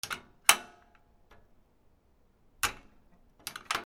金属カチャカチャ
/ M｜他分類 / L01 ｜小道具 / 金属
『カチャ』